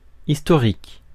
Ääntäminen
US : IPA : /ˈlɑɡ/ UK : IPA : /ˈlɒɡ/ US : IPA : /lɔɡ/